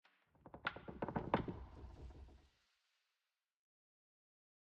Minecraft Version Minecraft Version 1.21.5 Latest Release | Latest Snapshot 1.21.5 / assets / minecraft / sounds / block / pale_hanging_moss / pale_hanging_moss15.ogg Compare With Compare With Latest Release | Latest Snapshot
pale_hanging_moss15.ogg